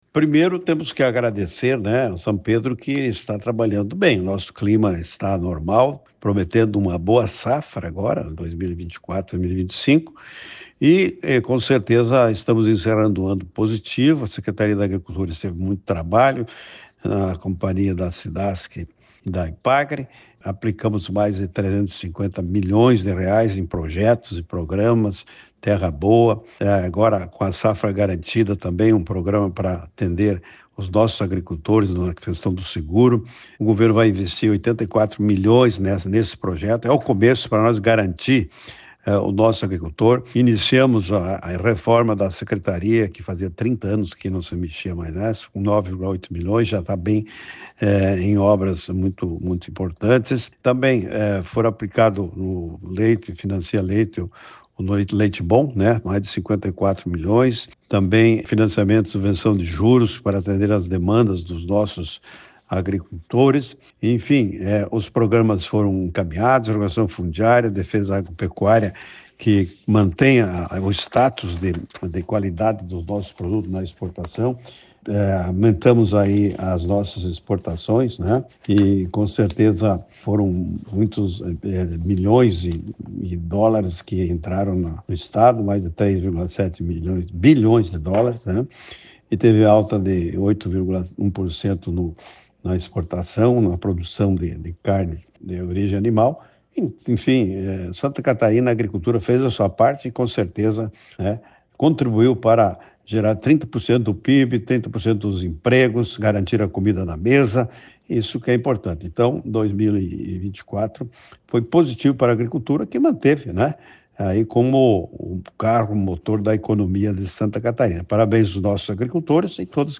RETROSPECTIVA-SECOM-2024-Secretario-da-Agricultura.mp3